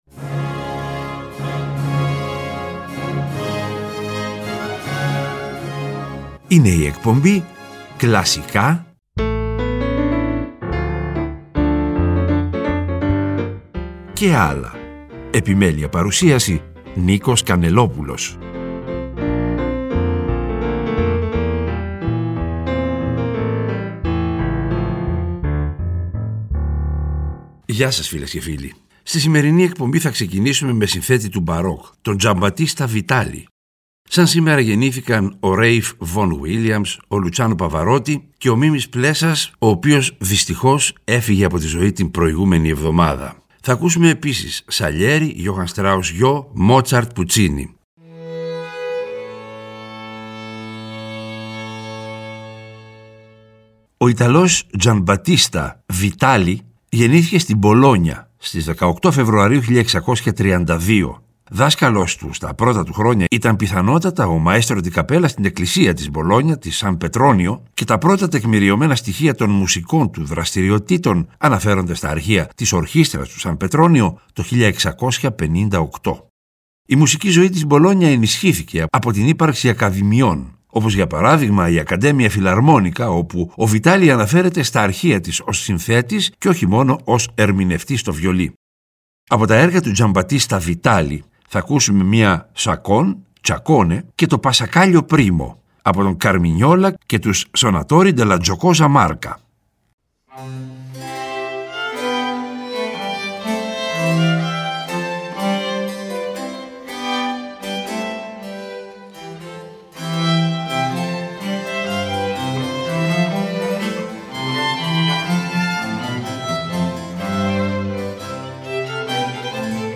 Ξεκινάμε με συνθέτη του Μπαρόκ, τον Vitali.